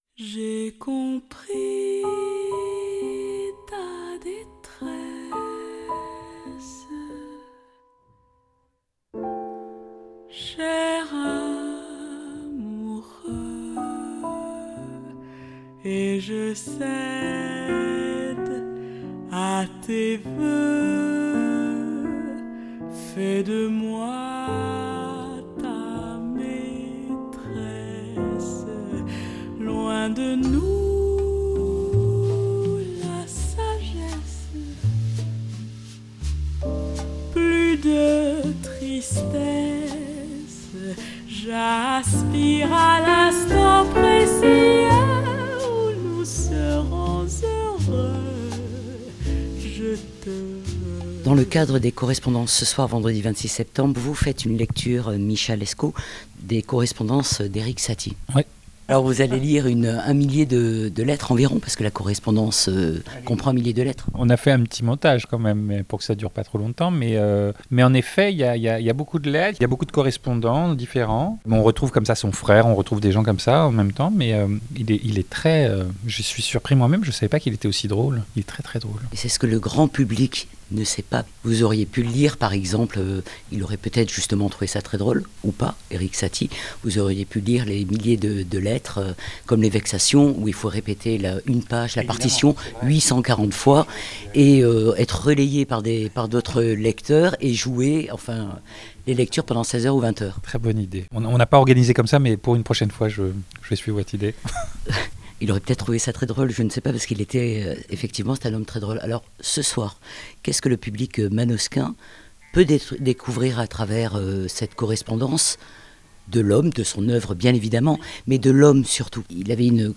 Au micro de Fréquence Mistral : Micha Lescot https